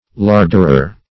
Larderer \Lard"er*er\, n. One in charge of the larder.